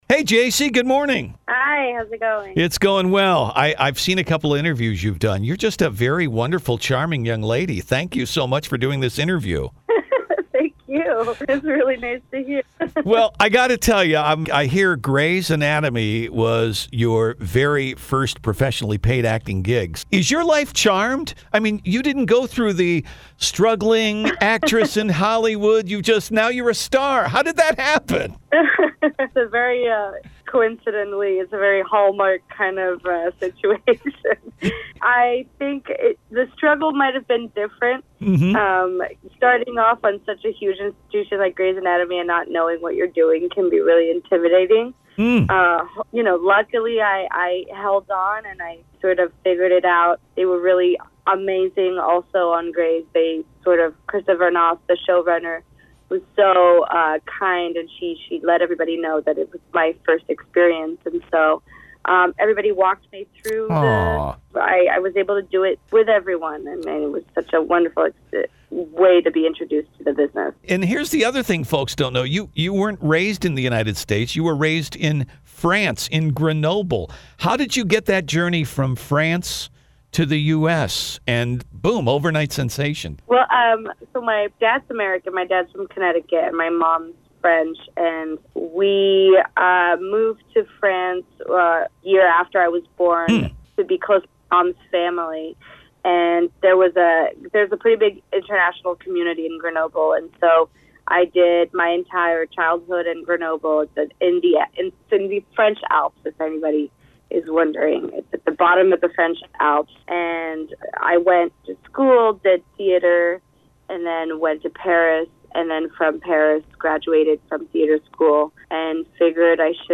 INTERVIEW https